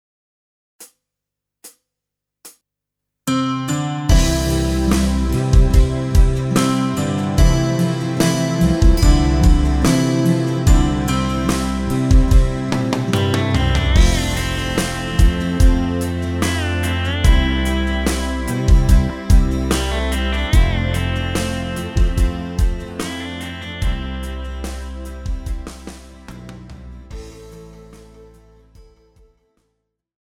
Žánr: Pop
BPM: 72
Key: C